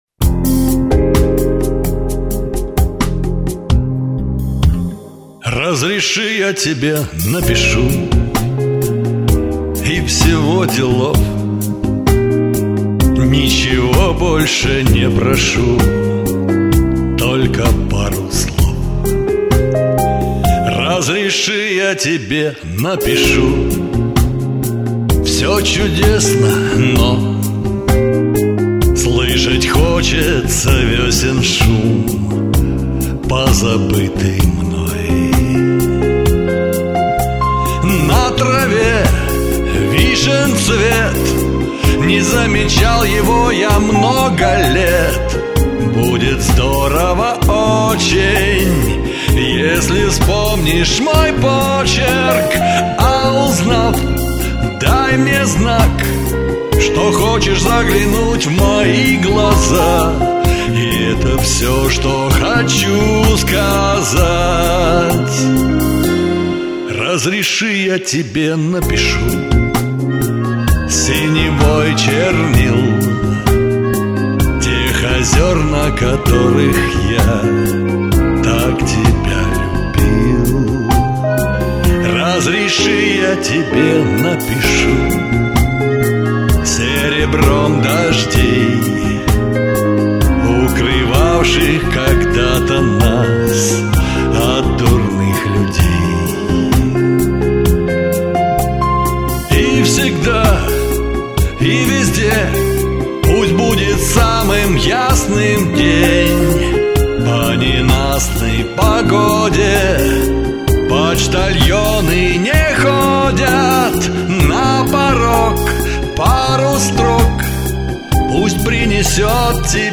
Комментарий инициатора: Условие одно: спеть как гимн!